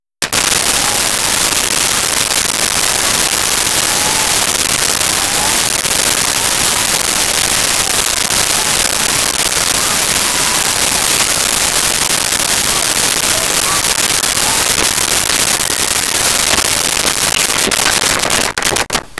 Crowd Applause Cheering
Crowd Applause Cheering.wav